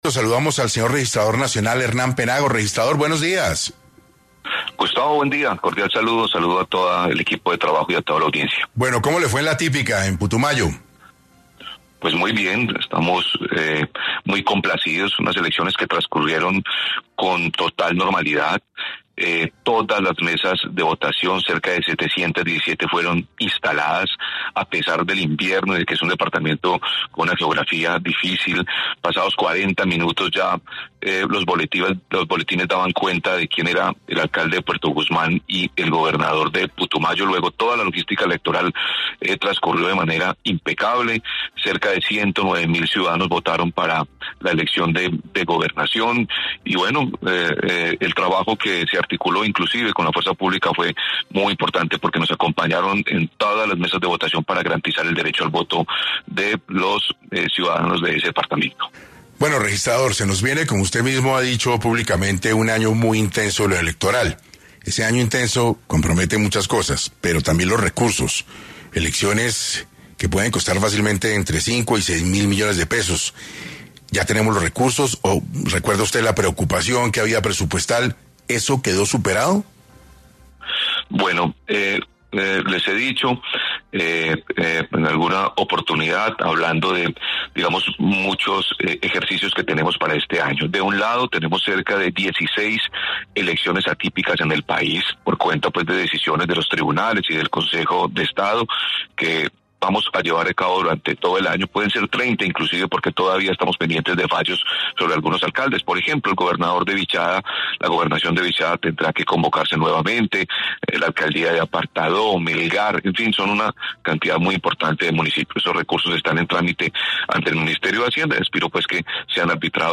En 6AM el Registrador Nacional Hernán Penagos habla del presupuesto general que existe en la registraduría y cómo serán las próximas votaciones a la presidencia.